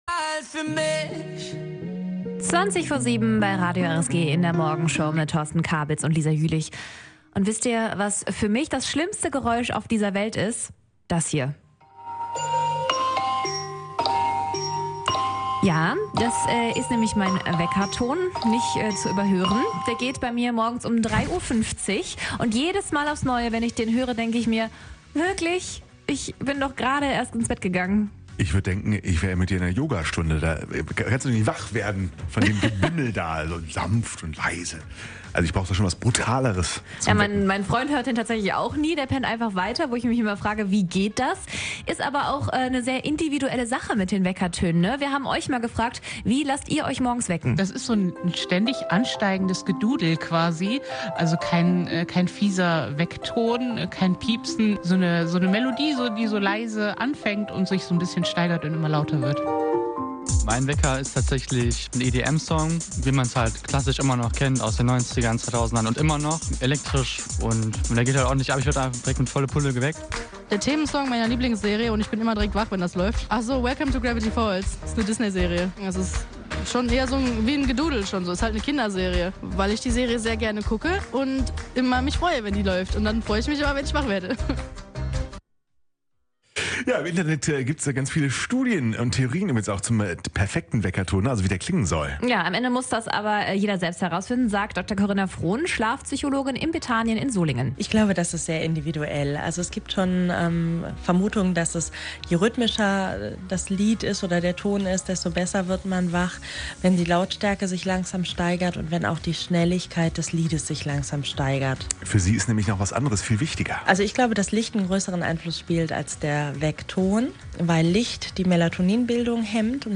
Wir haben gefragt: wie lasst ihr euch wecken? Und haben auch mit einer Schlafpsychologin drüber gesprochen.